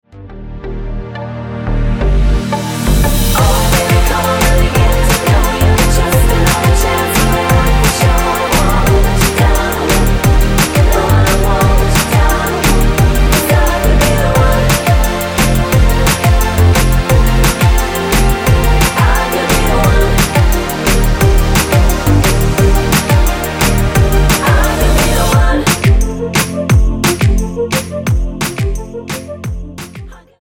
--> MP3 Demo abspielen...
Tonart:F mit Chor